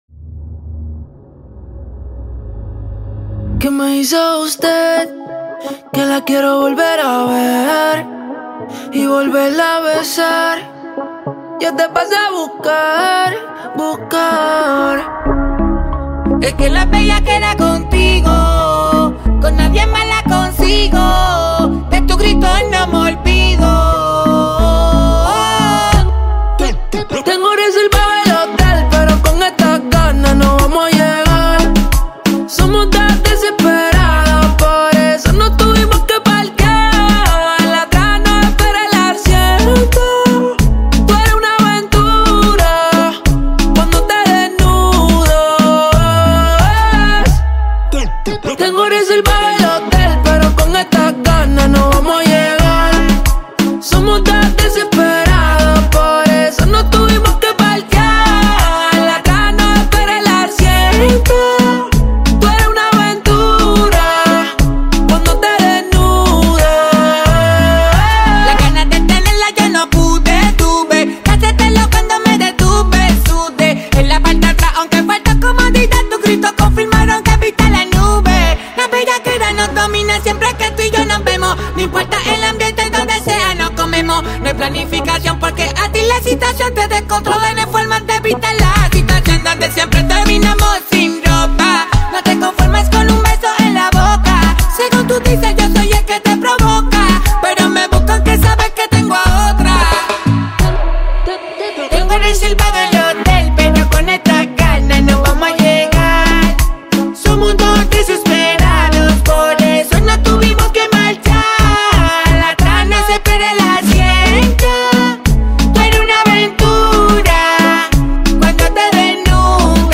el género de su música es sobre todo reggueton.